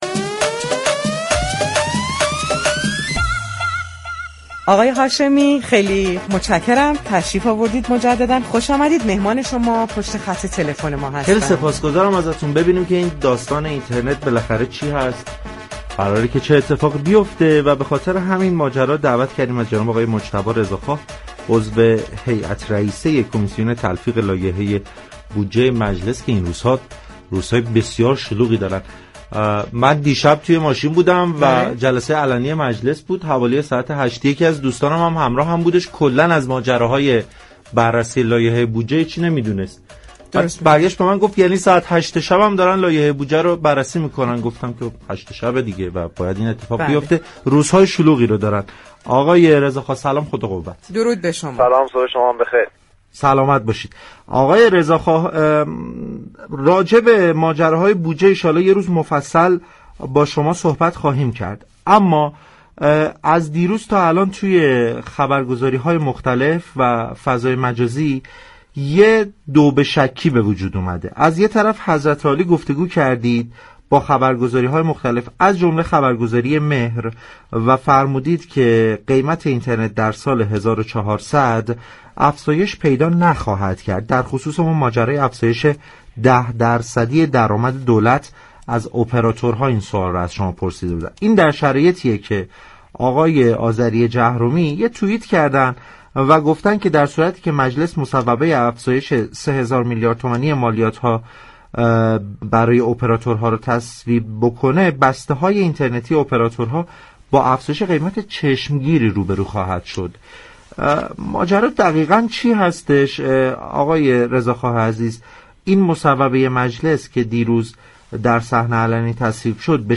برای آگاهی بیشتر از زوایای مختلف این مصوبه و خبرهای منتشر شده برنامه پارك شهر با مجتبی رضا خواه عضو هیات رئیسه كمیسیون تلفیق لایحه بودجه مجلس گفتگو كرد.